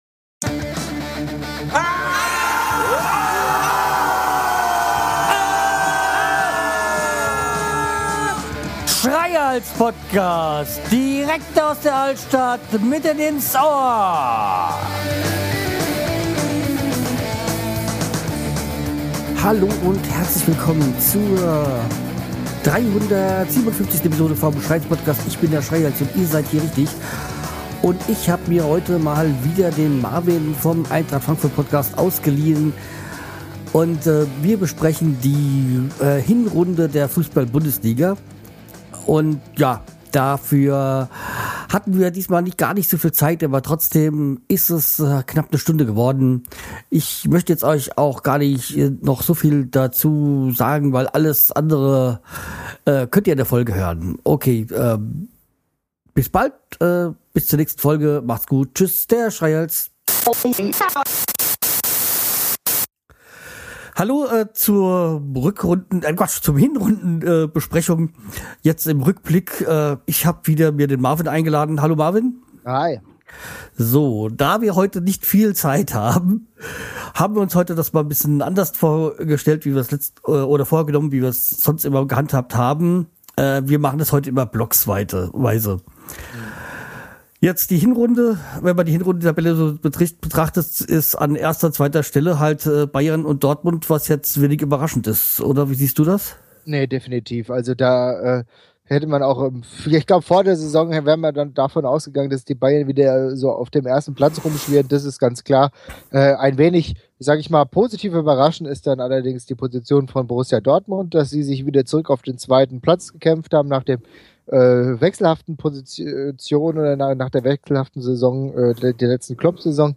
Das war dann wohl auch erstmal das letzte mal das ich eine Aufnahme über Skype getätigt habe.